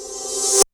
REVERSAL.WAV